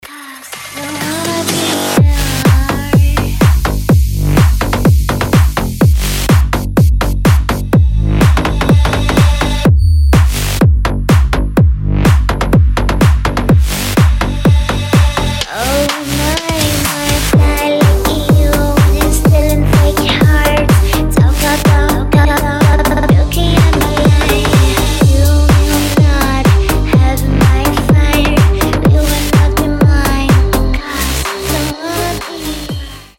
• Качество: 320, Stereo
громкие
жесткие
мощные
женский вокал
EDM
басы
Brazilian bass
энергичные